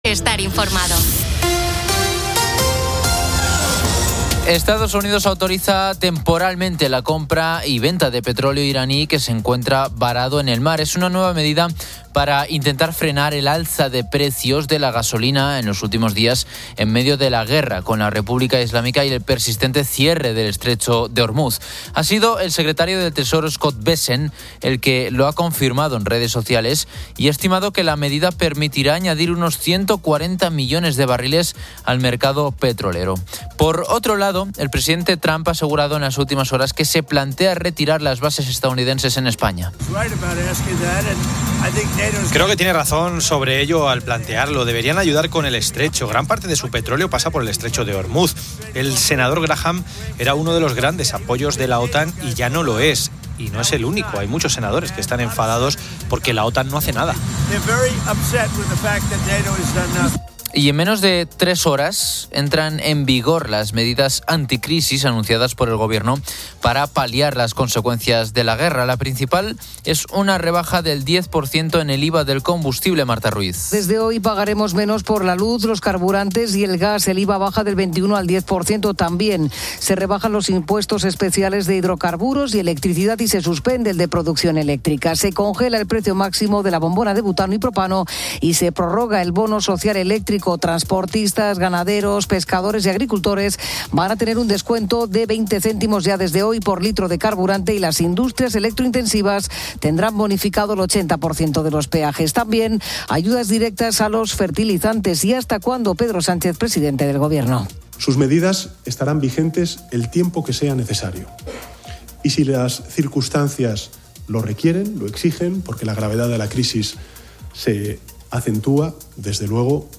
Finalmente, se presentan las novedades deportivas de La Liga y MotoGP, y una emotiva conversación con un camionero de Palencia subraya la importancia del sector logístico en la vida cotidiana.